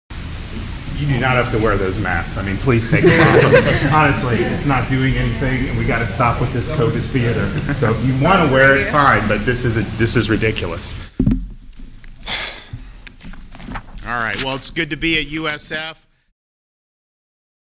GovRonDeSantis annoyed with USF students—